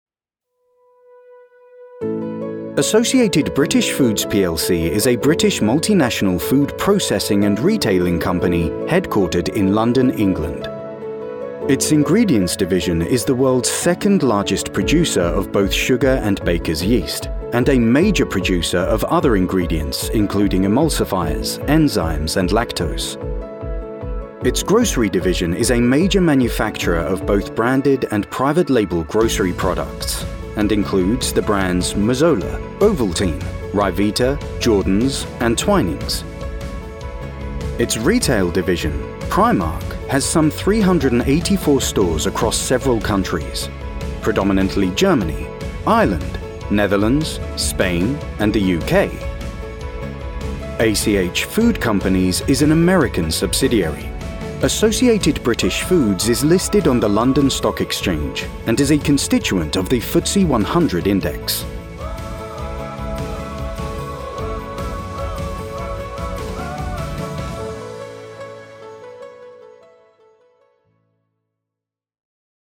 Anglais (Britannique)
Commerciale, Profonde, Naturelle, Distinctive, Enjouée
Vidéo explicative